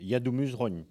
Locution ( parler, expression, langue,... )